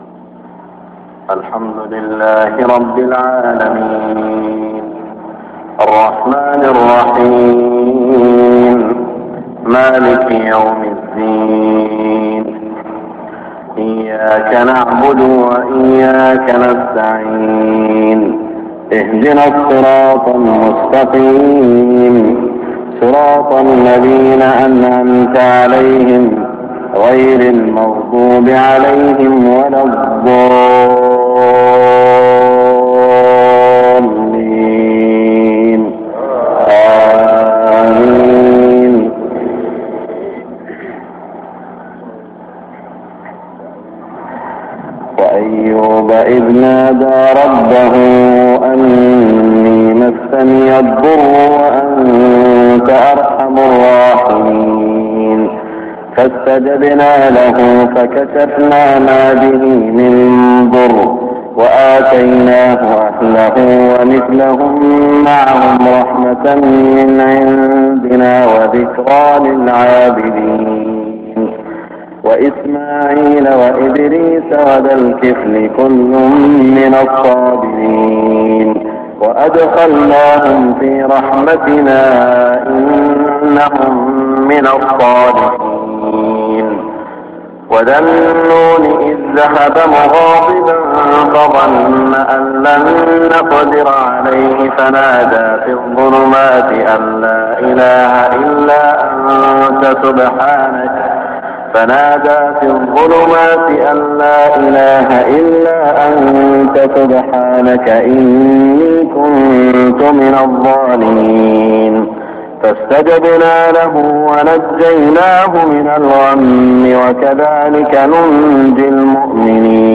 صلاة الفجر 1420هـ من سورة الأنبياء > 1420 🕋 > الفروض - تلاوات الحرمين